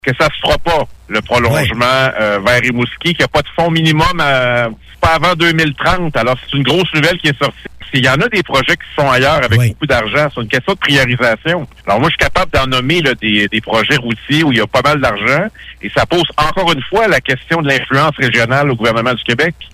Le député Pascal Bérubé a aussi commenté ce qui suit :